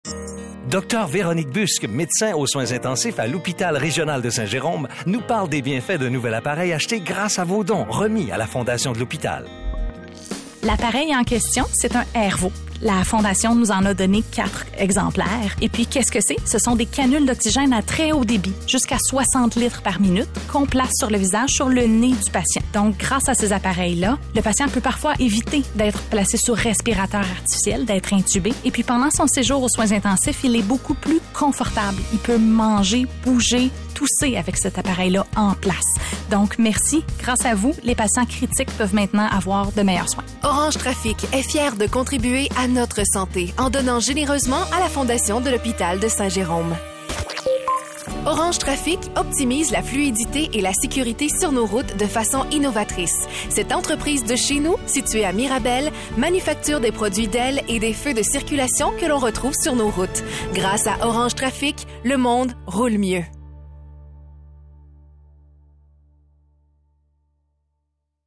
Orange Traffic sur les ondes de CIME FM!